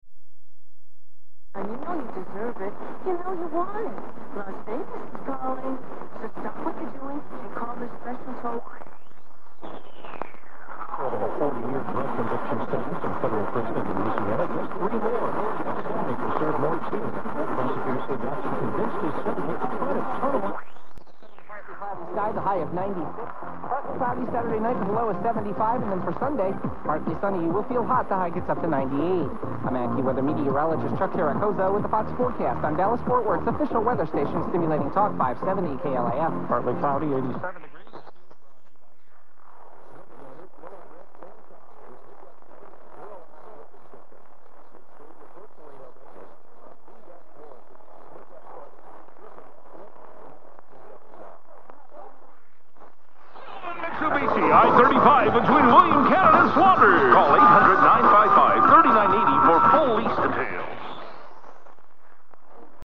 This recording was taken directly from the radio's headphone output into the recorder line-in